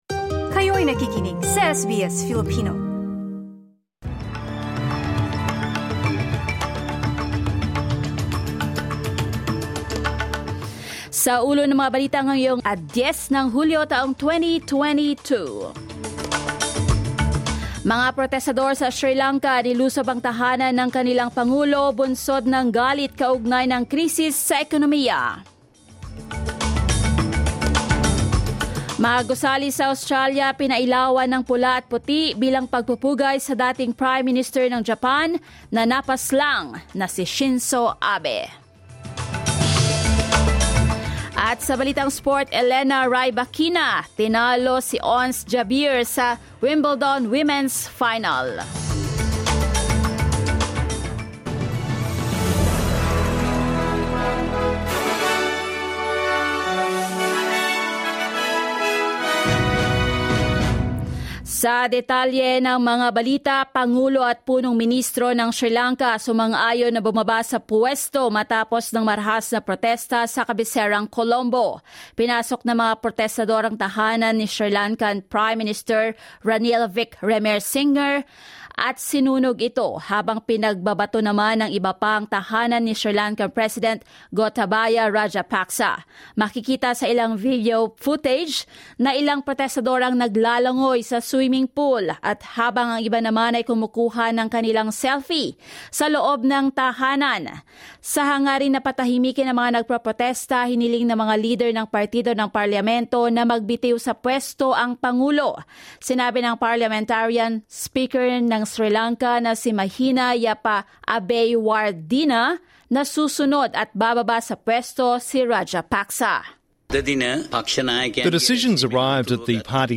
SBS News in Filipino, Sunday 10 July